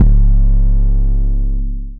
Doorz808_YC.wav